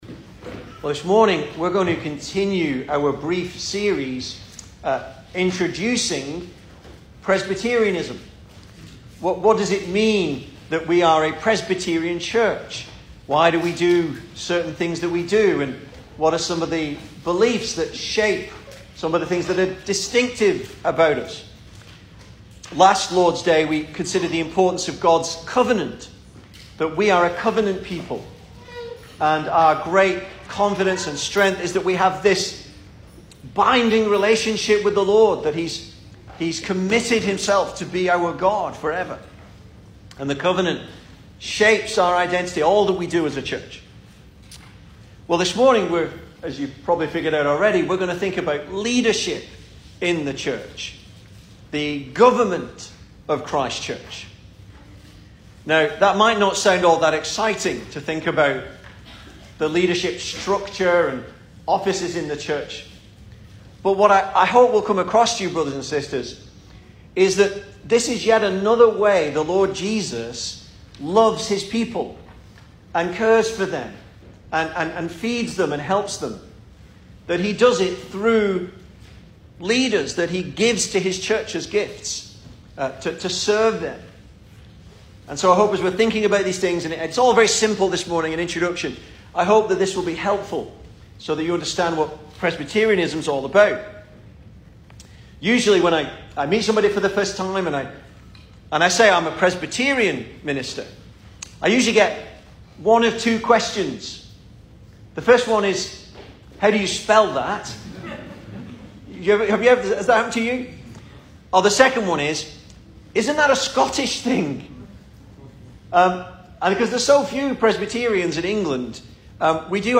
2022 Service Type: Sunday Morning Speaker